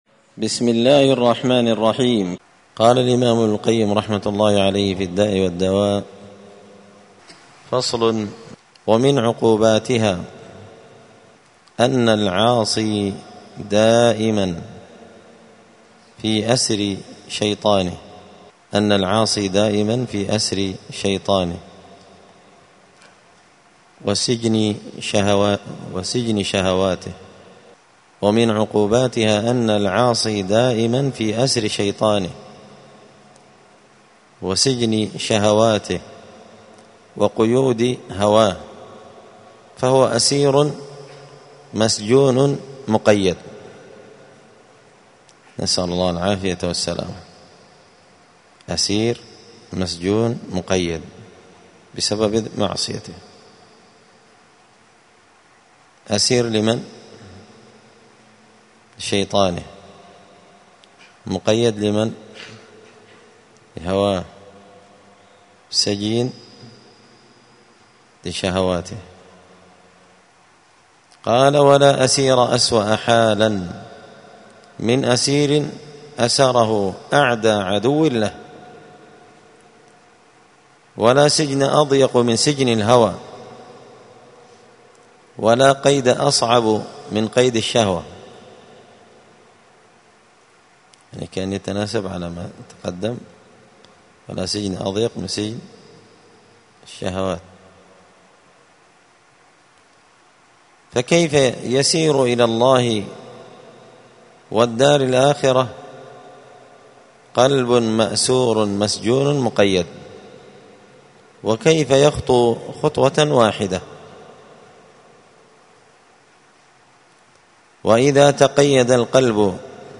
الجمعة 13 شعبان 1445 هــــ | الداء والدواء للإمام ابن القيم رحمه الله، الدروس، دروس الآداب | شارك بتعليقك | 43 المشاهدات